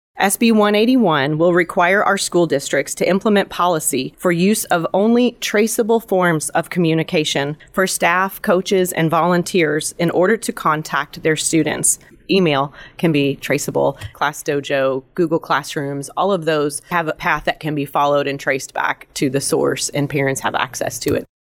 Senate Bill 181 was heard this week in the Senate Committee on Families and Children.
Senator Lindsey Tichenor detailed her sponsored bill during the meeting.(AUDIO)